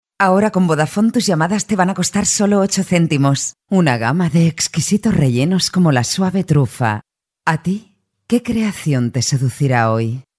kastilisch
Sprechprobe: Werbung (Muttersprache):